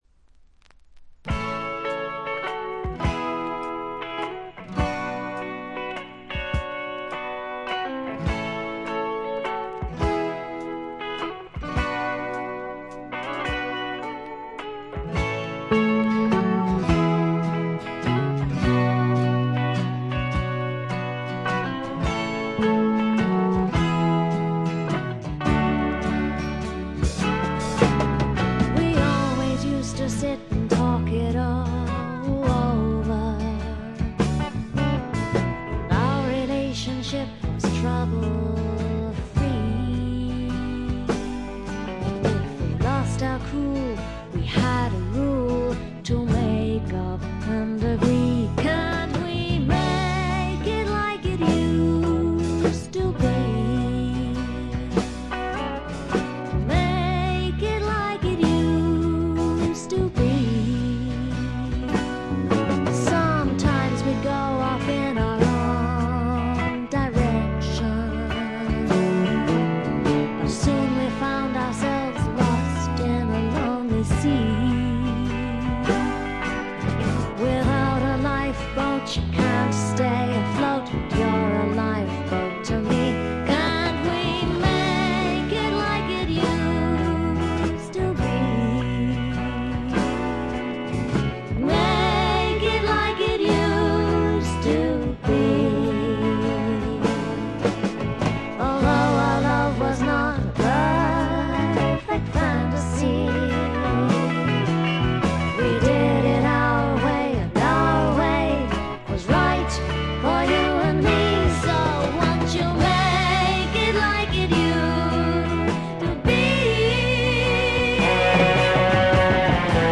静音部で微細なチリプチのみでほとんどノイズ感無し。
試聴曲は現品からの取り込み音源です。